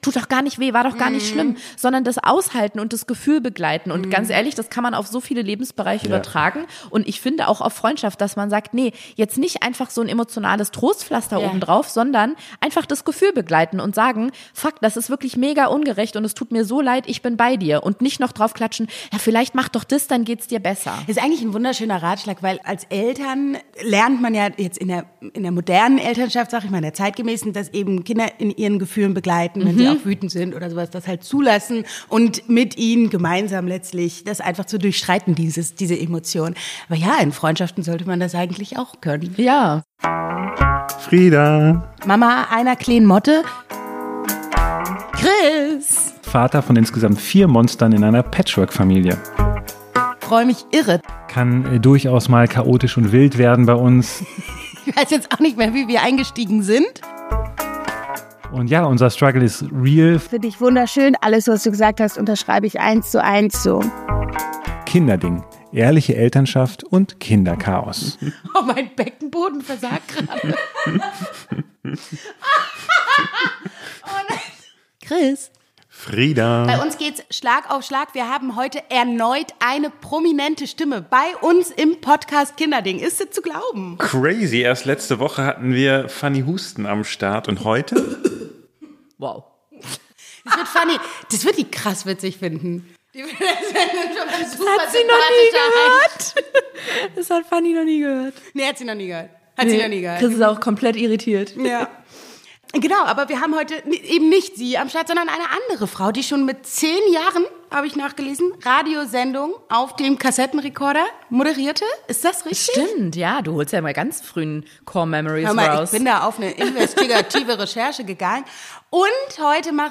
In dieser Folge ist Ariana Baborie zu Gast – Moderatorin, Autorin und bekannt aus Radio und Podcast.